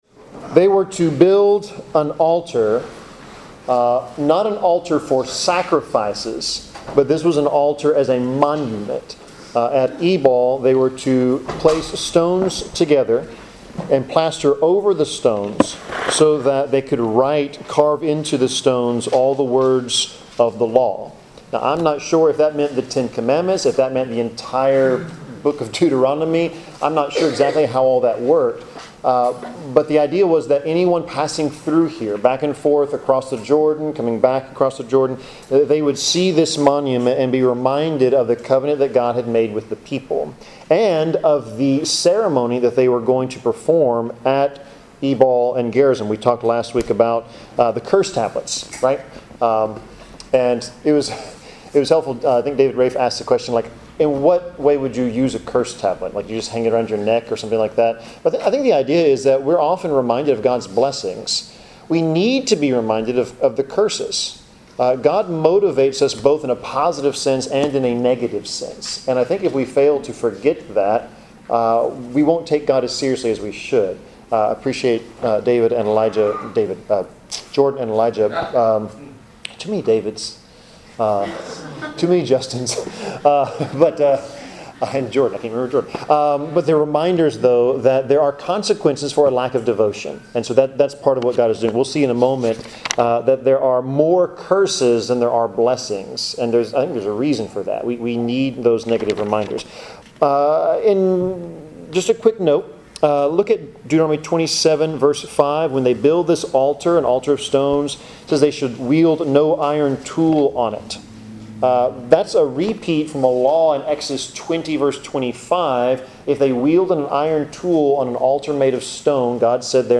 Bible class: Deuteronomy 27-28
Service Type: Bible Class Topics: Bible , Faith , Holiness , Jesus , Love , Obedience , Promises of God , Prophecy , Wrath of God